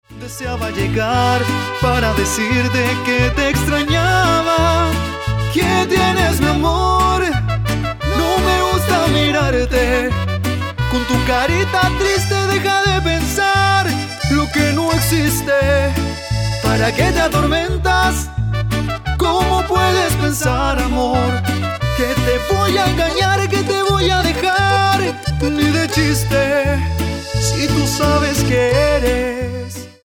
Una interpretación profunda y honesta